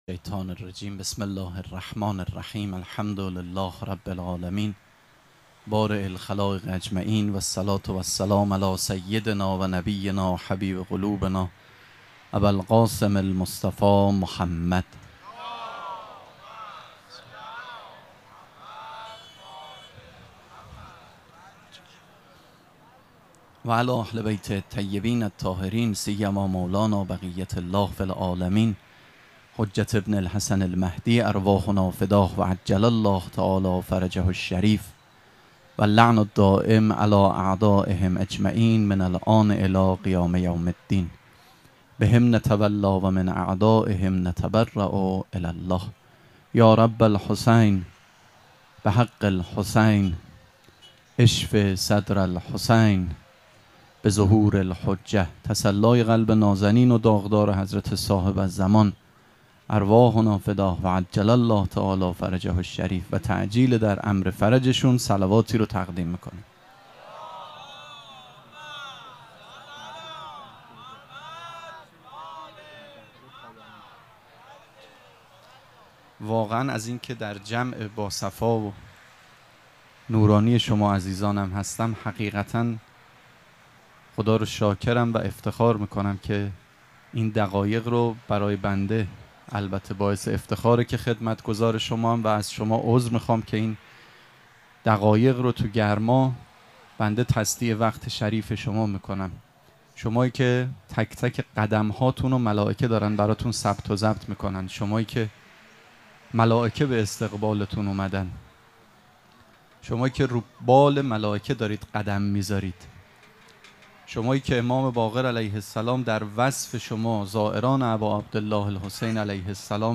سخنرانی
شب سوم مراسم عزاداری اربعین حسینی ۱۴۴۷ یکشنبه ۱۹ مرداد ۱۴۰۴ | ۱۶ صفر ۱۴۴۷ موکب ریحانه الحسین سلام الله علیها